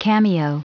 Prononciation du mot cameo en anglais (fichier audio)